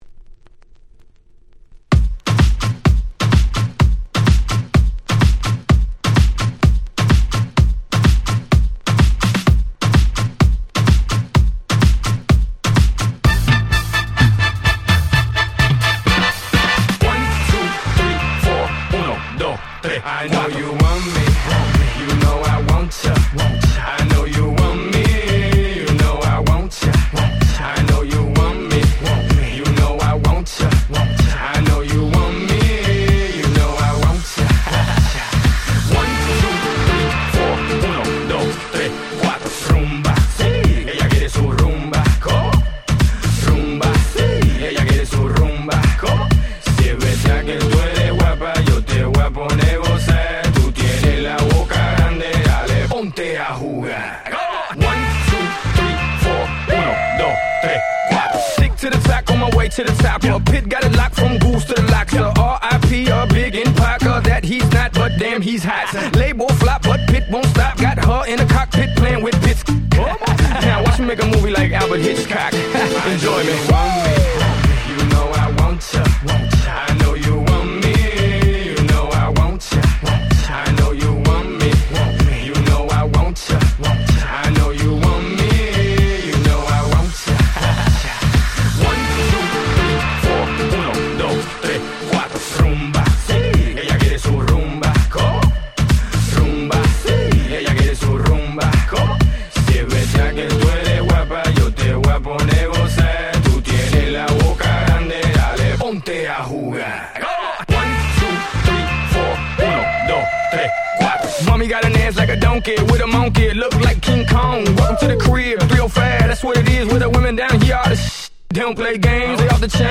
Hip Hop R&B